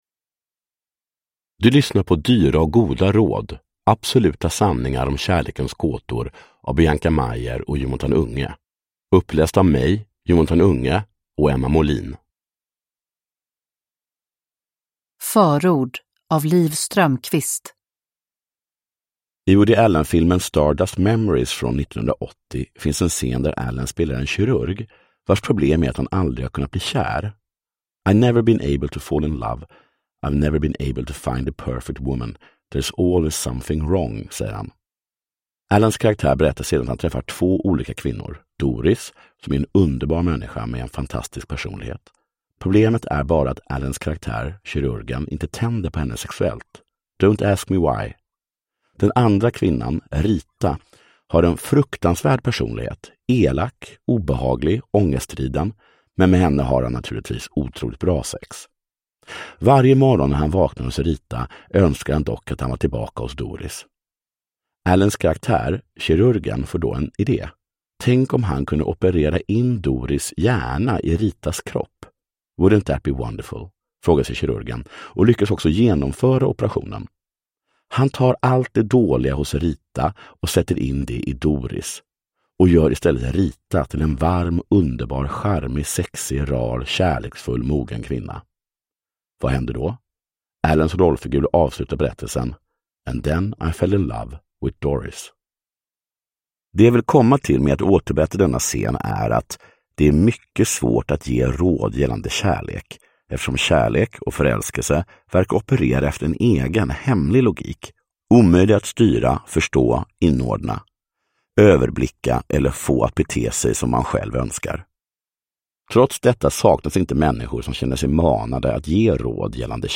Ljudbok
Uppläst av Emma Molin & Jonatan Unge.